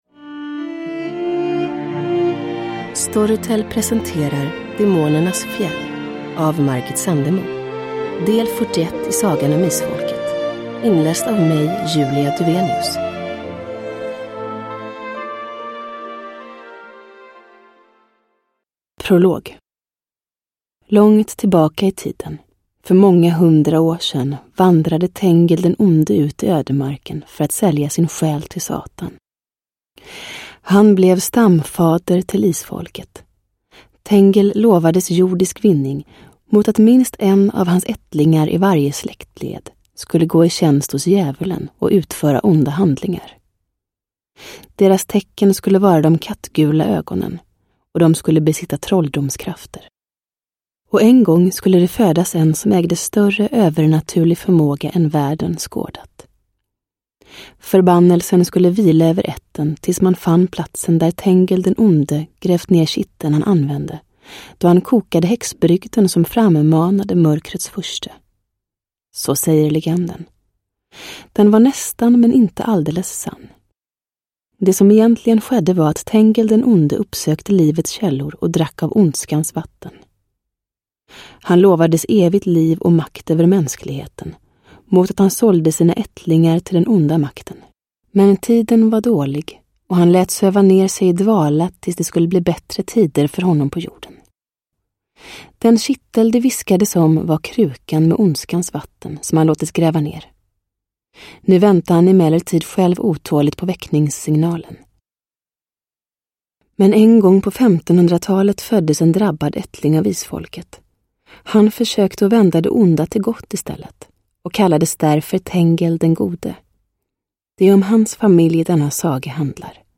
Därför samlades hela släkten vid Demonernas fjäll, där mycket skulle avslöjas - både på gott och ont... Sagan om Isfolket, nu äntligen som ljudbok. I suverän ny inläsning av Julia Dufvenius.
Uppläsare: Julia Dufvenius